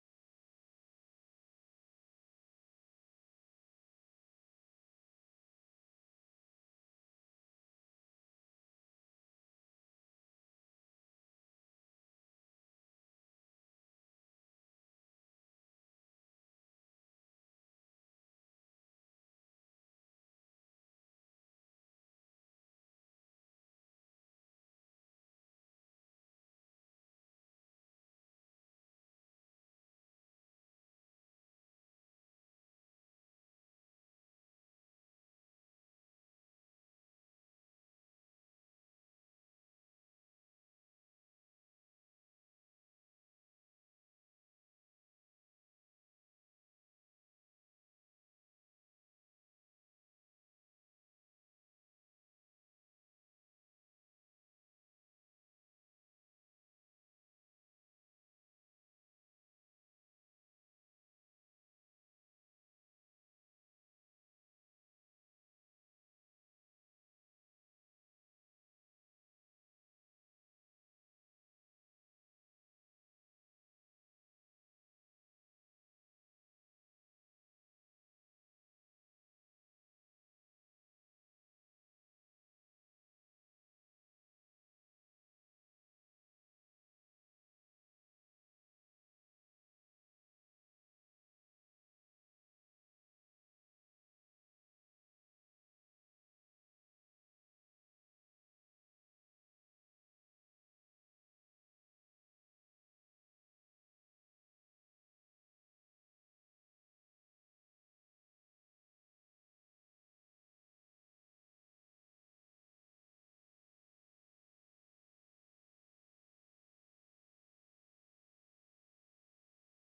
De vergadering wordt gehouden in zaal 008 in het Atrium met in achtneming van de 1,5 meter regel.